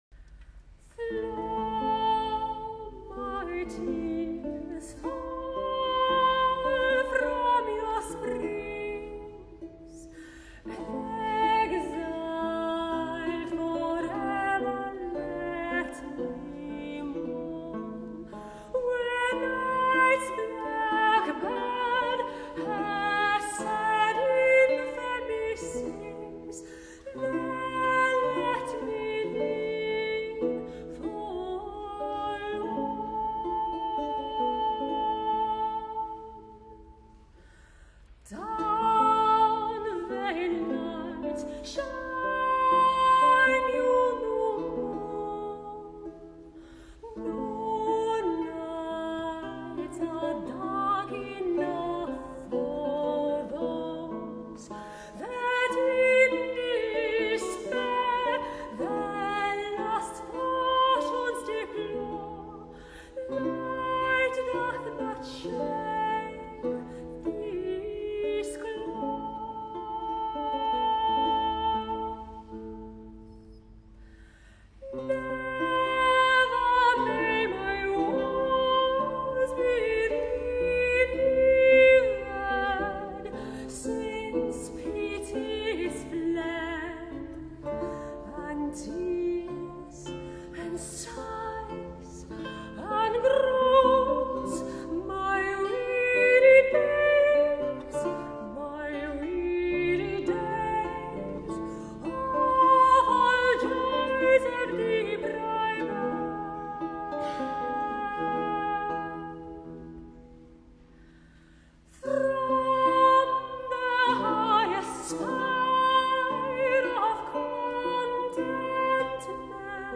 LIVE recording of John Dowland’s Flow My Teares from The Second Booke of Songes as performed by The Schoole of Night in August 2012 at the festival The Song of Our Roots in Jarosław, recorded by Polskie Radio Dwójka
soprano
lute